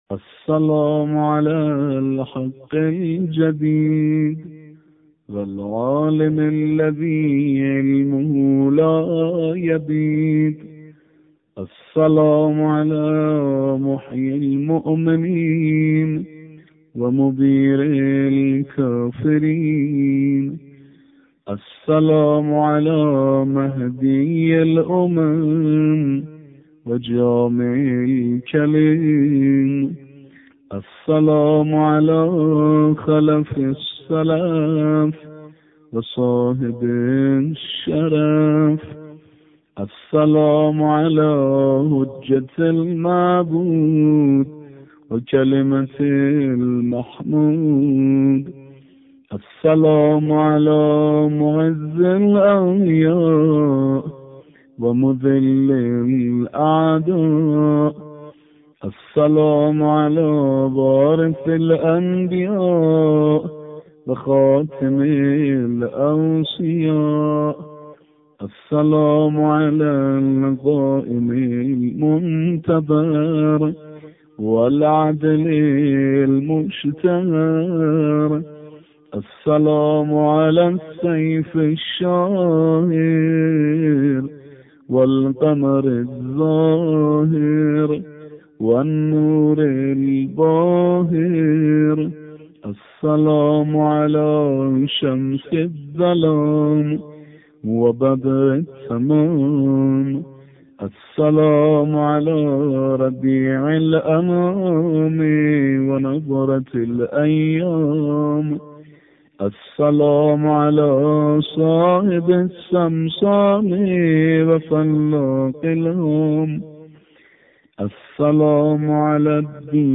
ভিডিও | মানশাবীর সুললিত কণ্ঠে সূরা আলে ইমরান
এই পর্বে মিশরের প্রসিদ্ধ ক্বারি "মানশাবী"এর সুললিত কণ্ঠে সূরা আলে ইমরানের ১৭৩ নম্বর আয়াতের মনোমুগ্ধকার তিলাওয়া তুলে ধরা হল: